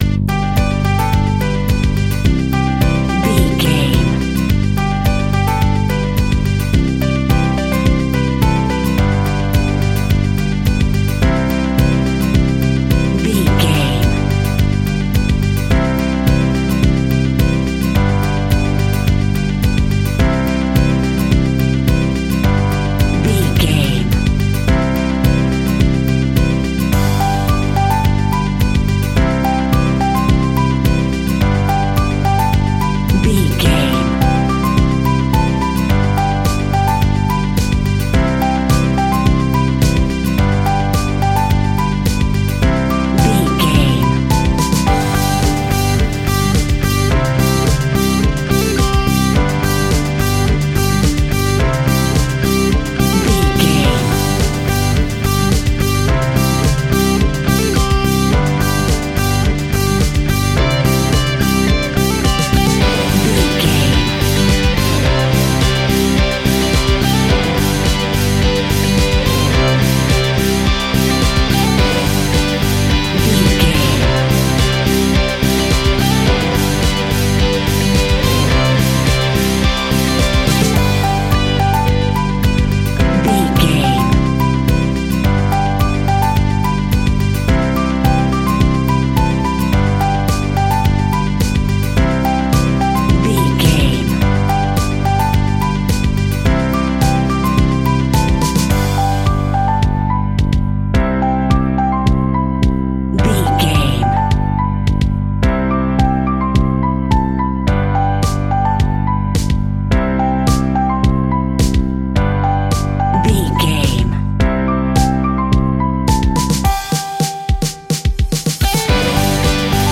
Ionian/Major
pop rock
indie pop
fun
energetic
uplifting
instrumentals
upbeat
groovy
guitars
bass
drums
piano
organ